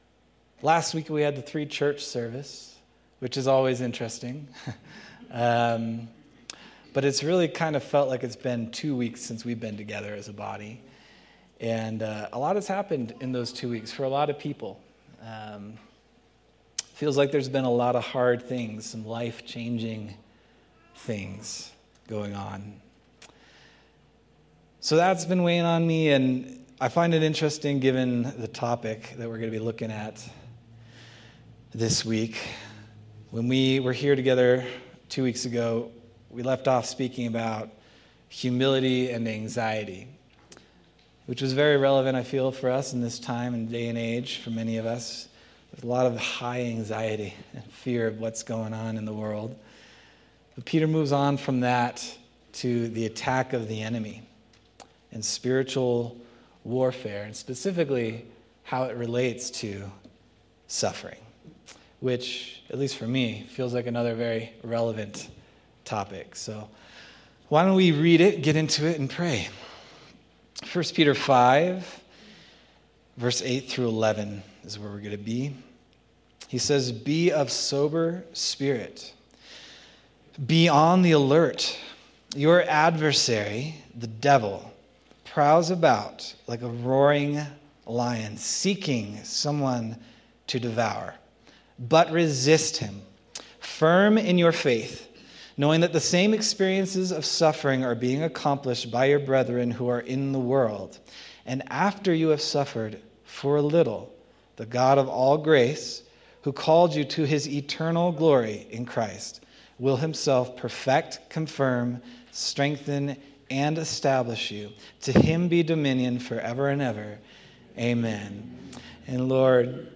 August 17th, 2025 Sermon